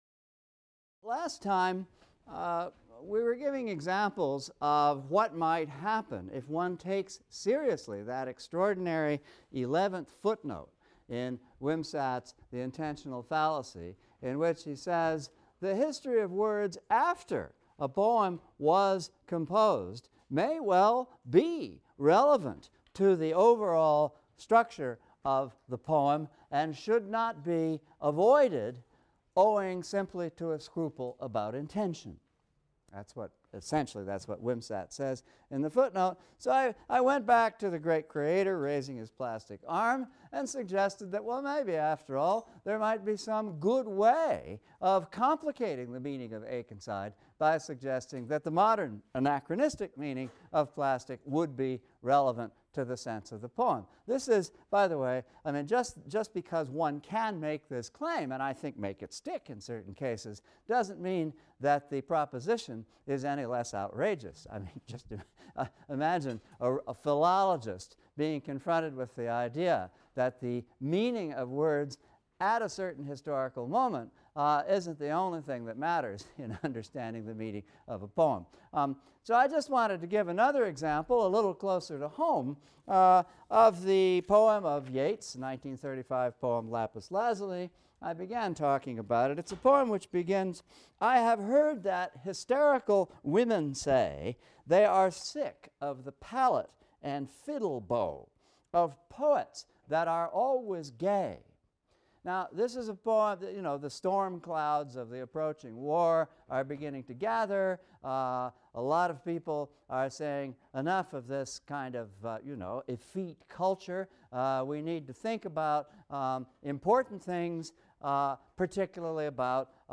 ENGL 300 - Lecture 6 - The New Criticism and Other Western Formalisms | Open Yale Courses